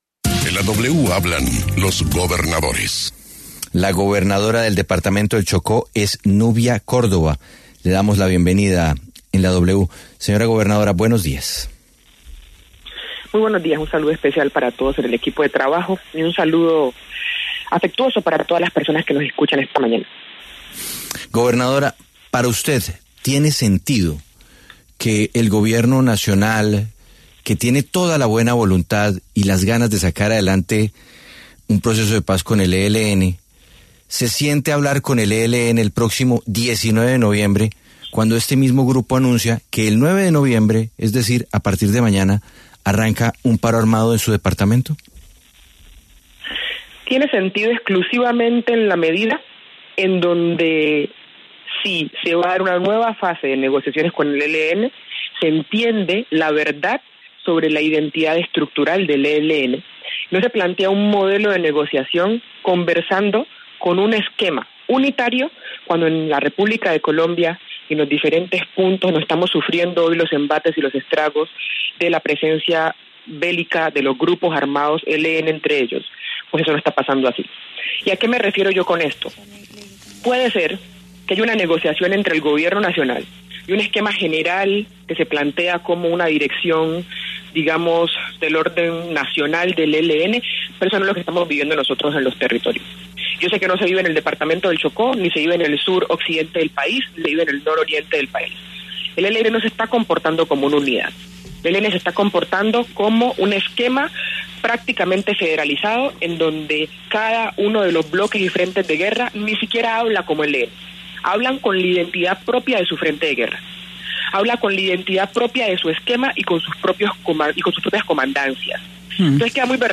Nubia Carolina Córdoba, gobernadora del Chocó, conversó con La W sobre el anuncio del ELN de un paro armado en el departamento y el posible regreso a una negociación con el Gobierno.